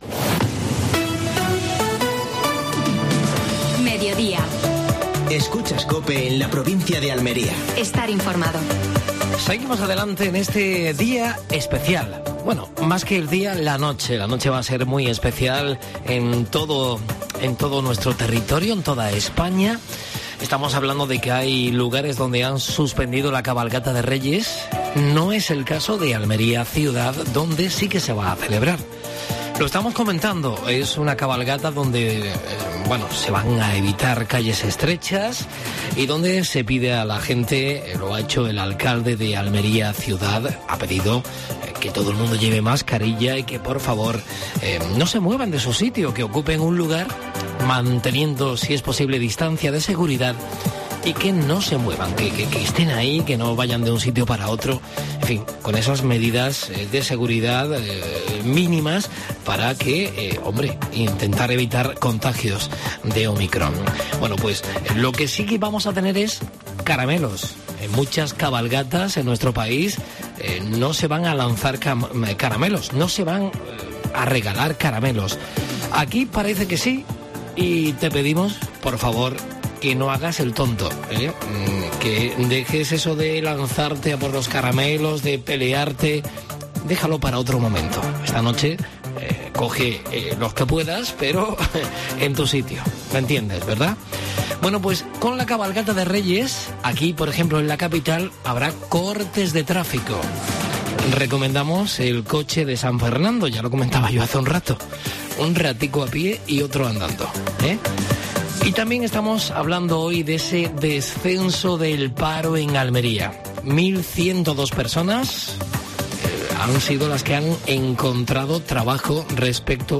Actualidad en Almería. Entrevista